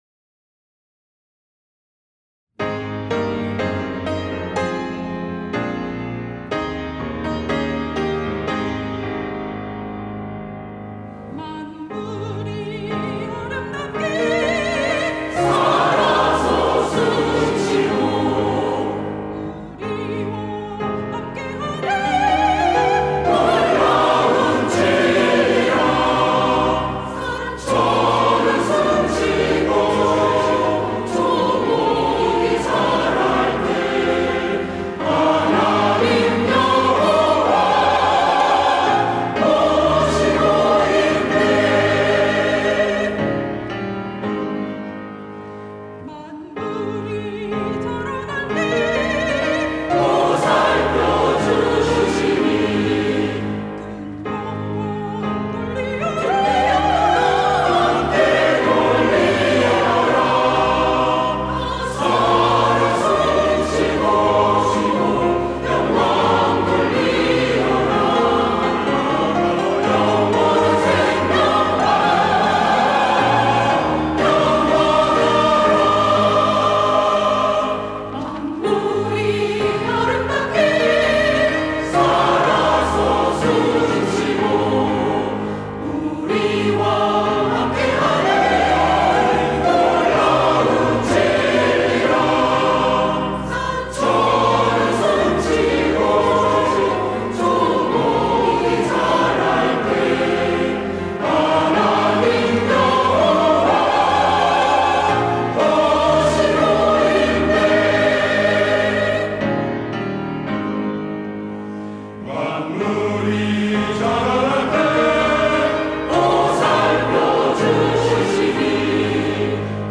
시온(주일1부) - 영원하라
찬양대